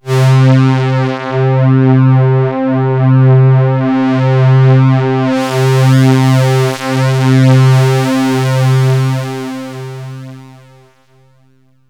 STRINGS 0012.wav